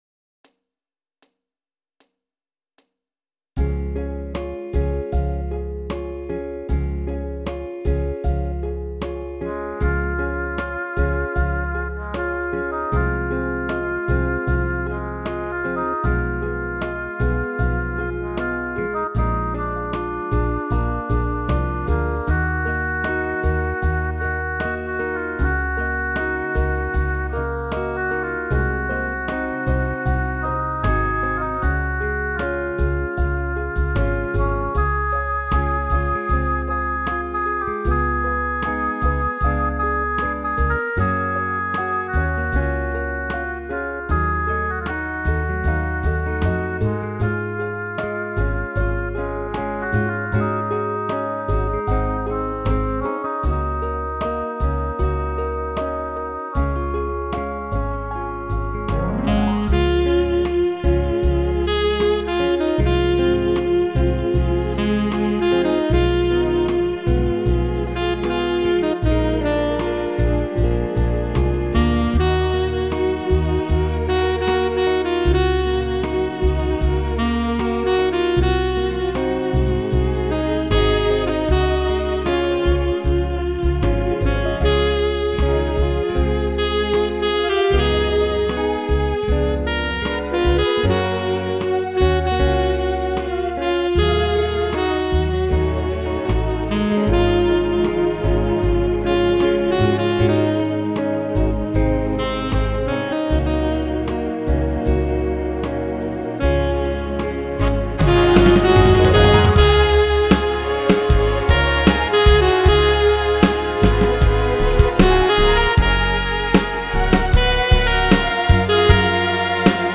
thzhjt55gn  Download Instrumental